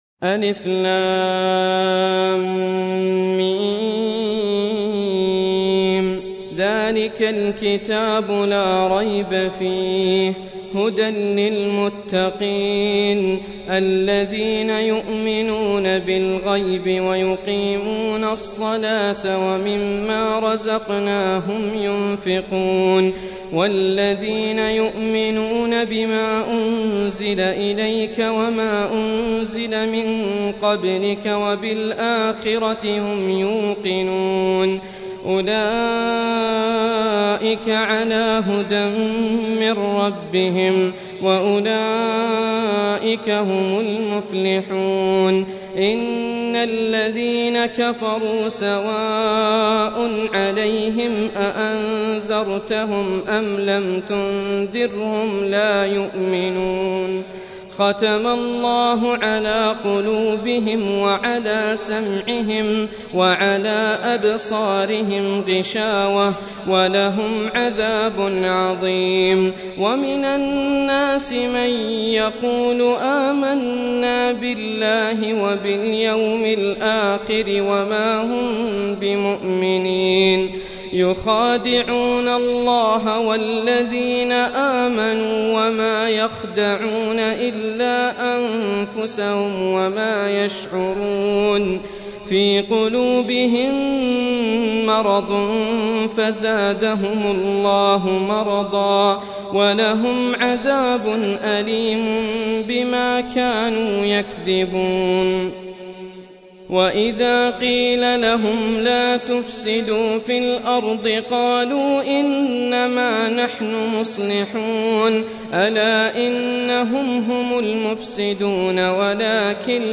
Surah Repeating تكرار السورة Download Surah حمّل السورة Reciting Murattalah Audio for 2. Surah Al-Baqarah سورة البقرة N.B *Surah Includes Al-Basmalah Reciters Sequents تتابع التلاوات Reciters Repeats تكرار التلاوات